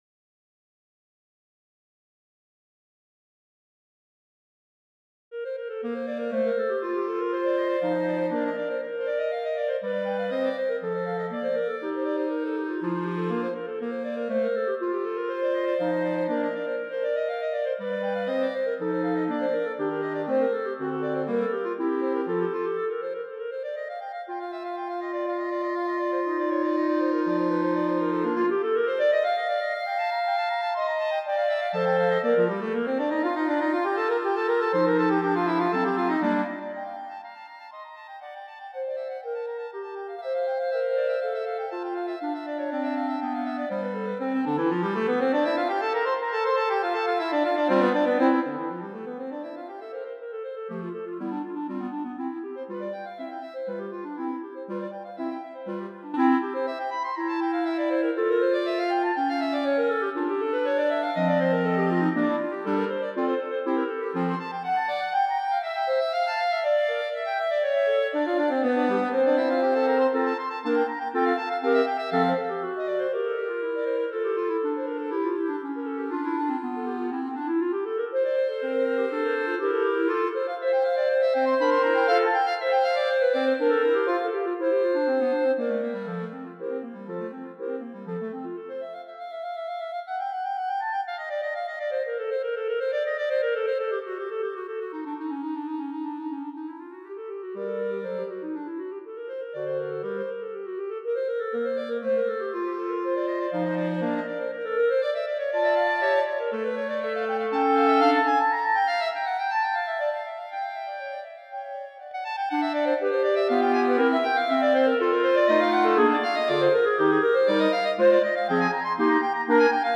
Perpetuum Mobile für 4 Klarinetten